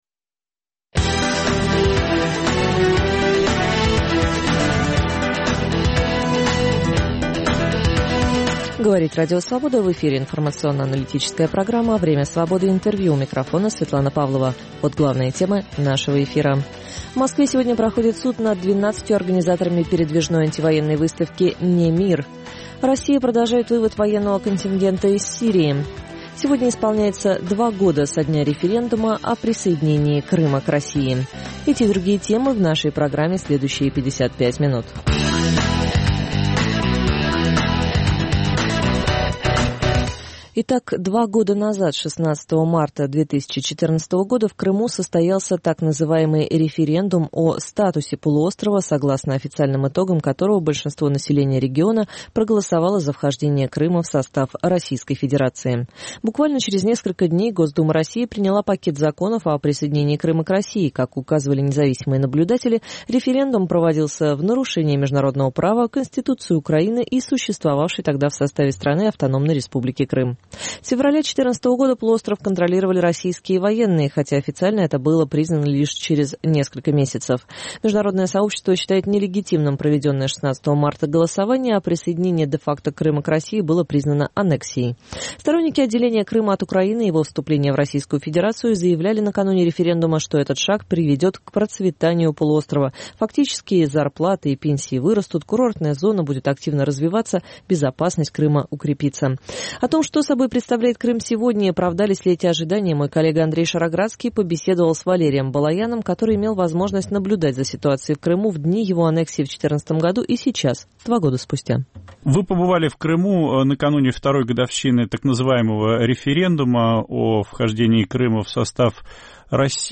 Время Свободы - Интервью